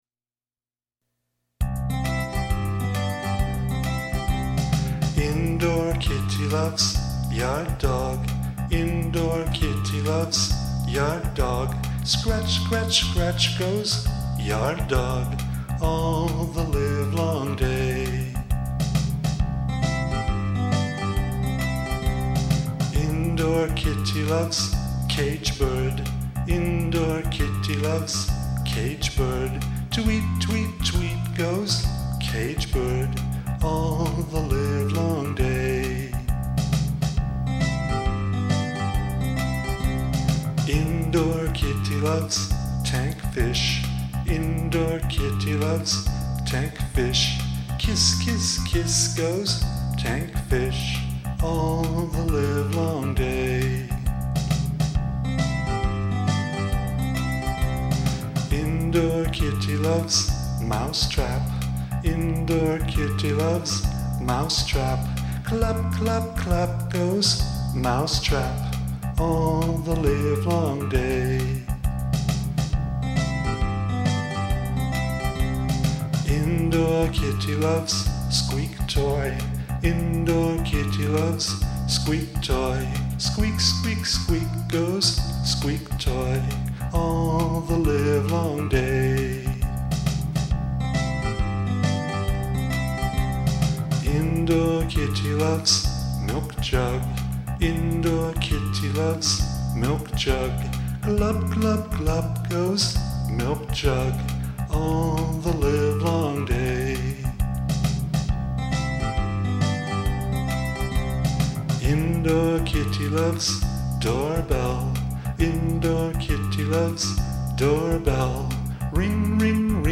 my whimsical children's song "Indoor Kitty"
lo-fi